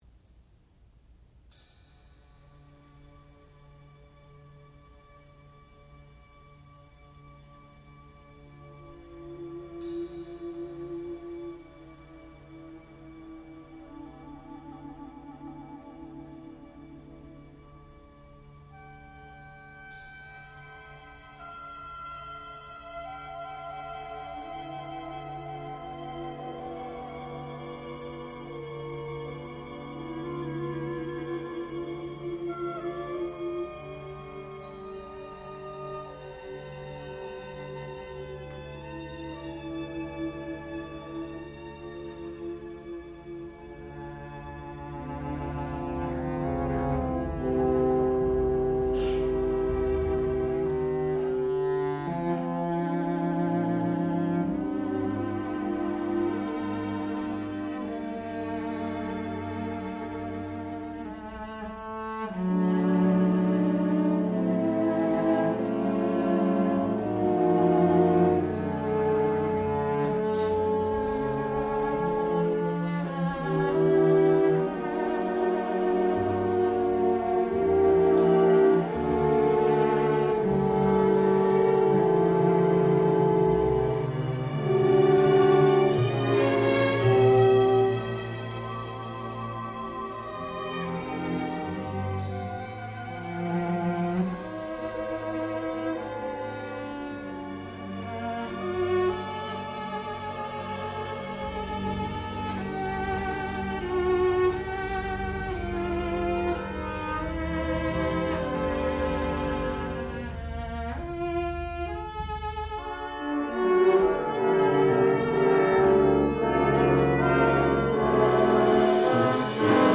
Nocturne for violoncello and orchestra
sound recording-musical
classical music